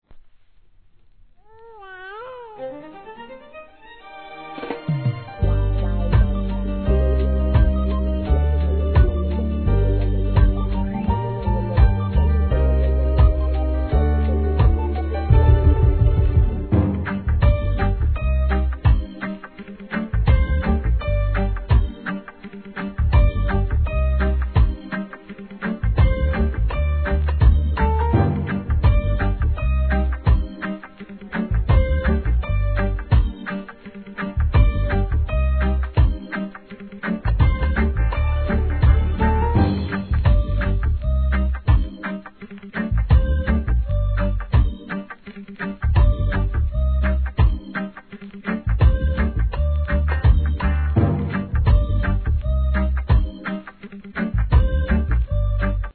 REGGAE
哀愁RHYTHM